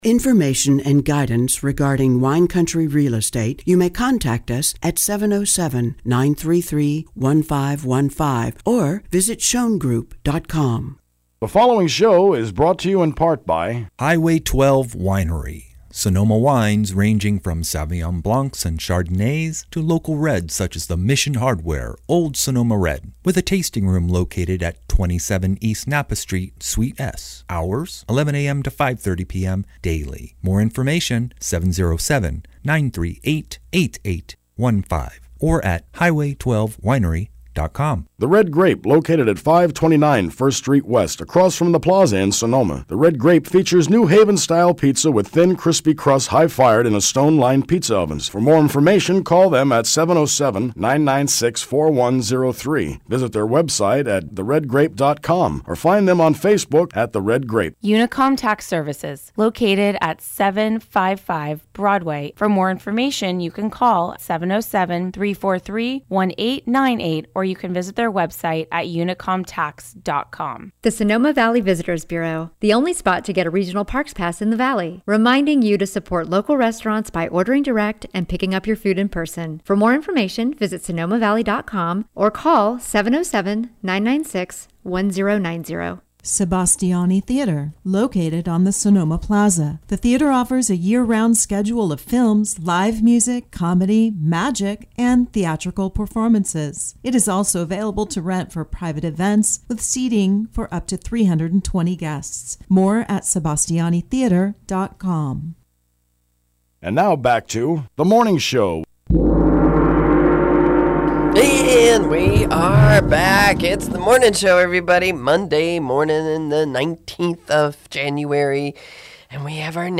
KSVY 91.3 FM Radio